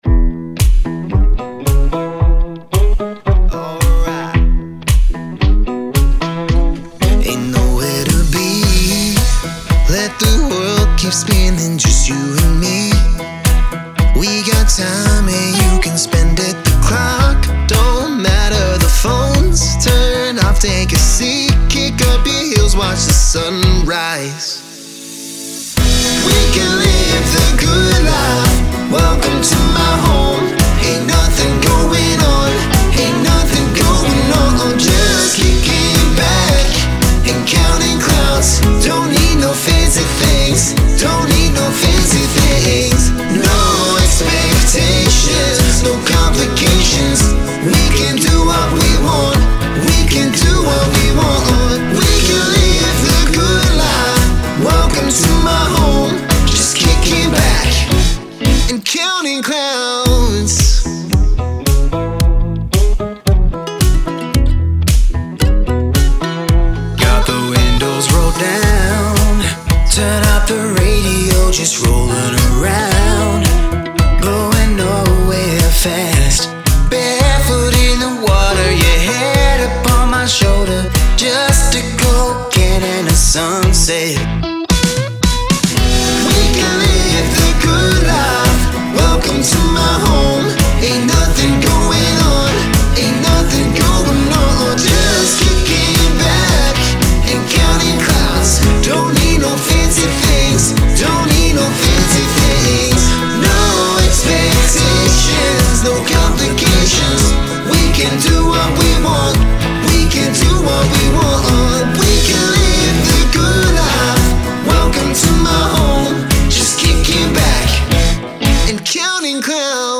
country-pop duo
vibrant and feel-good new single
rich country-blues sound, delivering an uplifting anthem